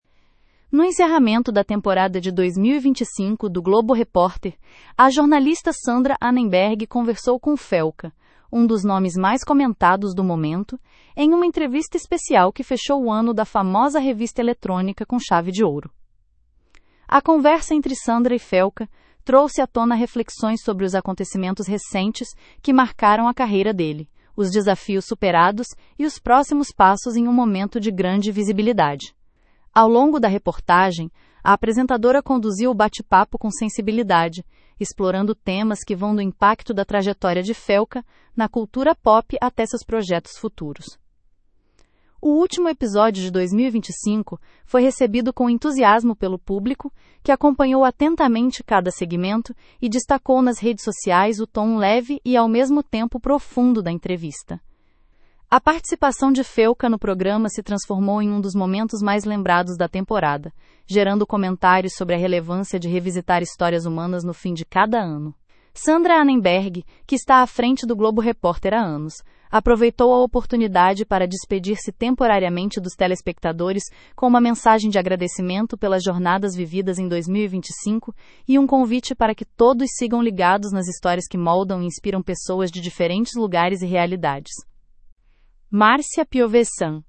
Sandra Annenberg entrevista Felca no último Globo Repórter de 2025
No encerramento da temporada de 2025 do Globo Repórter, a jornalista Sandra Annenberg conversou com Felca, um dos nomes mais comentados do momento, em uma entrevista especial que fechou o ano da famosa revista eletrônica com chave de ouro.